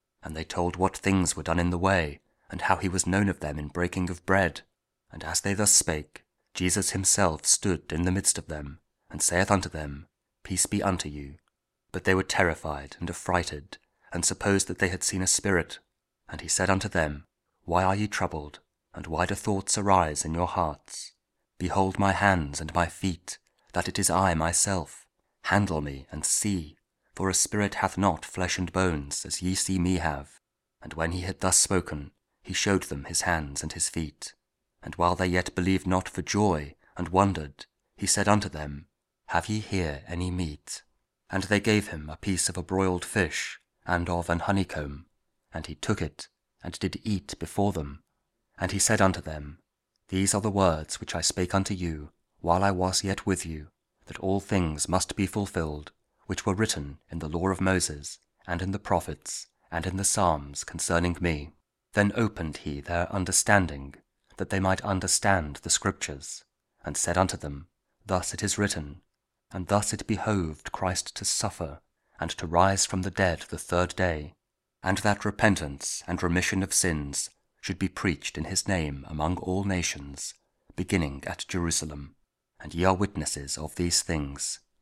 Luke 24: 35-48 Audio Bible KJV | King James Audio Bible | King James Version | Daily Verses